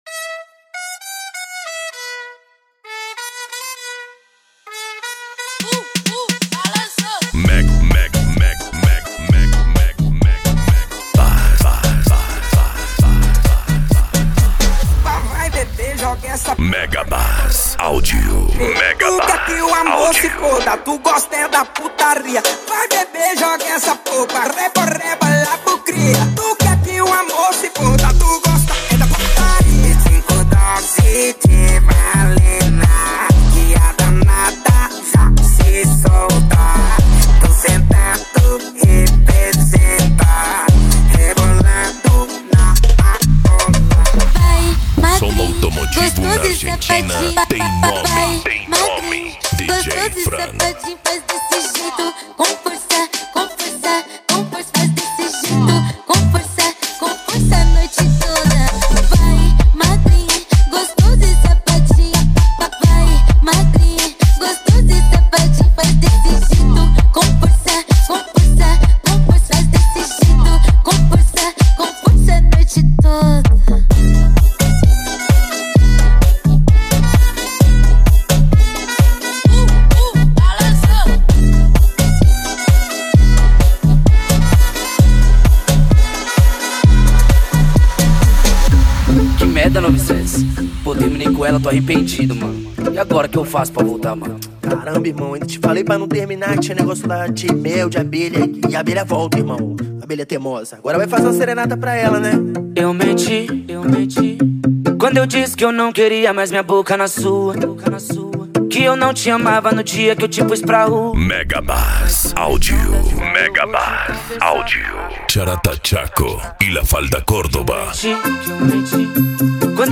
Bass
Funk
Mega Funk
Minimal